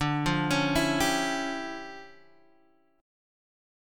DmM11 chord